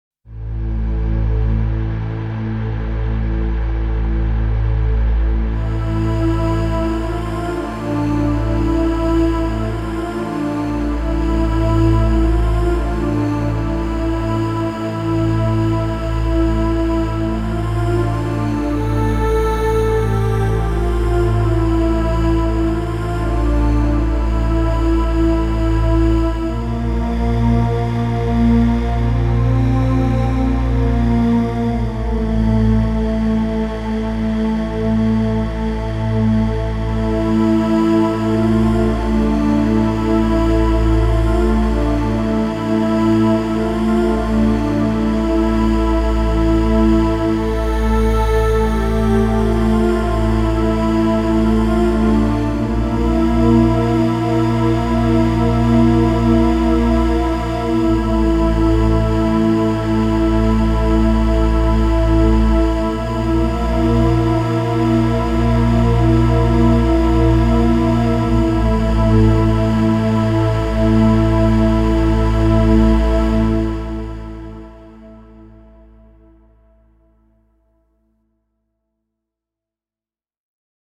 Ethereal Mysterious Choir Ambience Sound Effect
Genres: Sound Effects